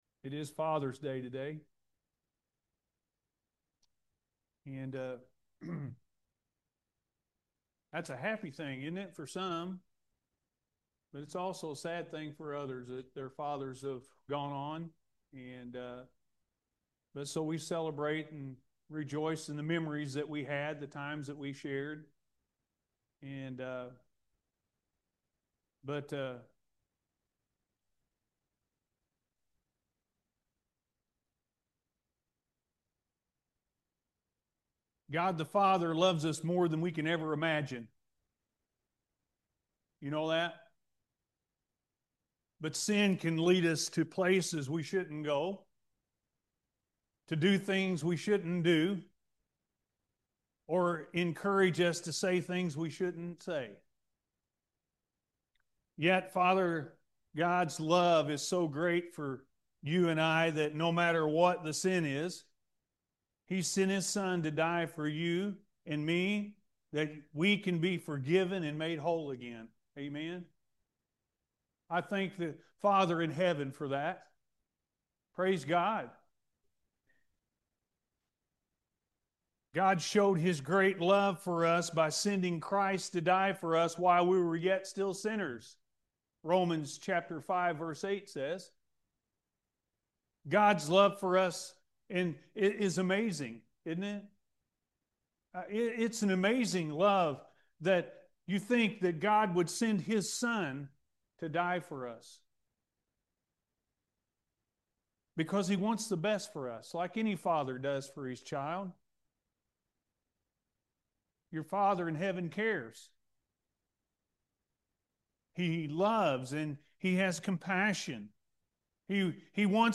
Experience The Love Of The Heavenly Father-A.M. Service